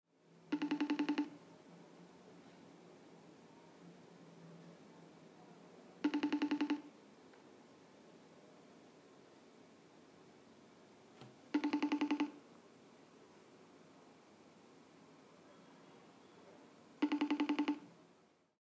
#8 Nieuw overgangsdeuntje voor FaceTime-gesprekken
FaceTime heeft een nieuw deuntje gekregen zodra je iemand via FaceTime belt. In plaats van de hardere pieptoon die achter elkaar afgespeeld wordt, is het nu een subtieler bons-geluidje dat veel minder aanwezig is dan het oude geluidje.
FaceTime-overgangsdeuntje.mp3